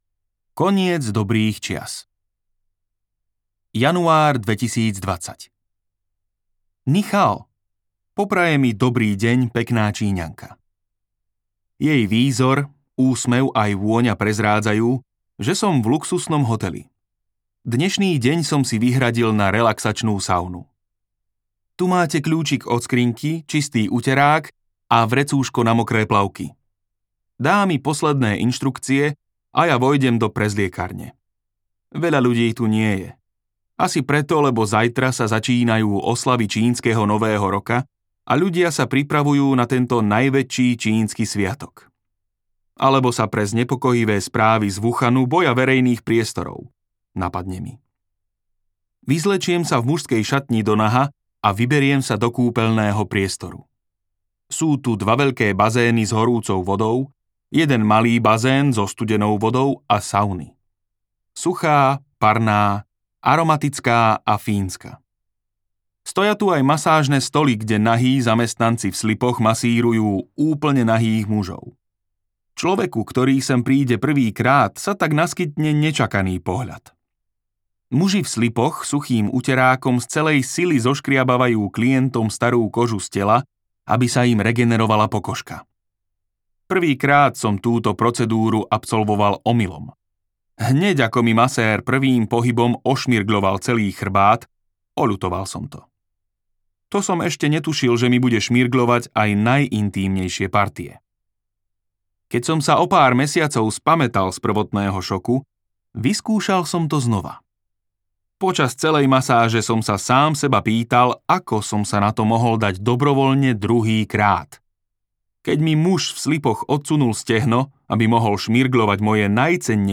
Ťažké časy v Číne audiokniha
Ukázka z knihy